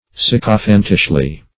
Search Result for " sycophantishly" : The Collaborative International Dictionary of English v.0.48: Sycophantish \Syc"o*phant`ish\, a. Like a sycophant; obsequiously flattering. -- Syc"o*phant`ish*ly , adv.
sycophantishly.mp3